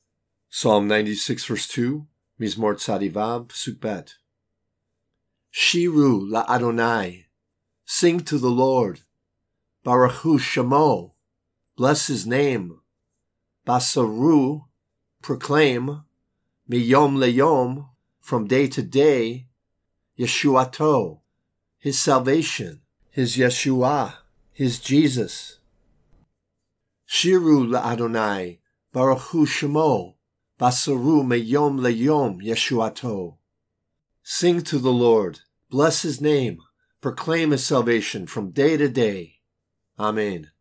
Psalm 96:2 reading (click for audio):